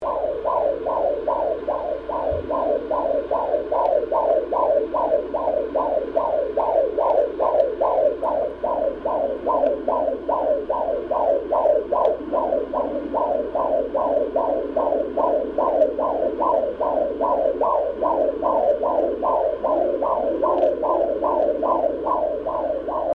• “Isn’t that neat? That heart beat is much faster than yours!”
FetalHeartTones_23sec.mp3